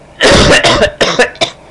Coughing Sound Effect
Download a high-quality coughing sound effect.
coughing.mp3